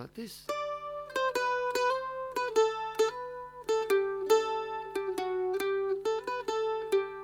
The Mandolin: A Versatile and Beloved String Instrument
Sound: Bright, high-pitched with a short sustain.
Mandolin-track-15.wav